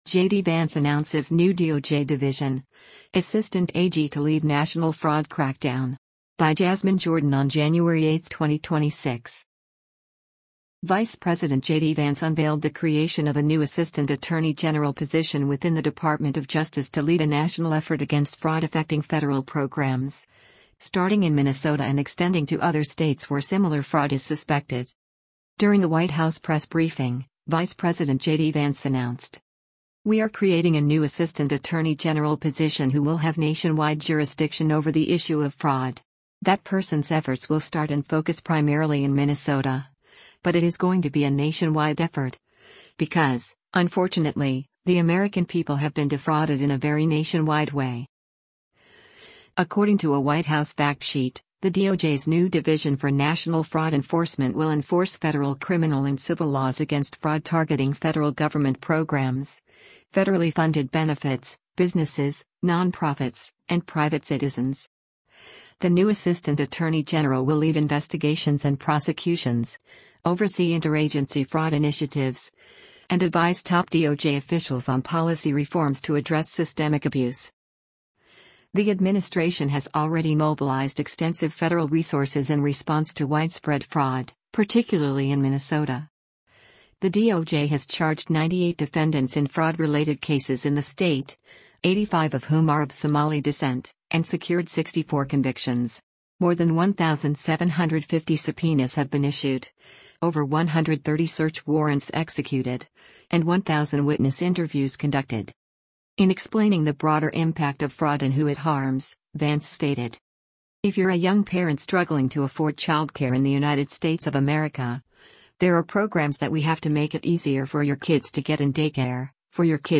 During a White House press briefing, Vice President JD Vance announced:
Vice President Vance directly addressed Minnesota Governor Tim Walz during the press briefing in response to a reporter’s question: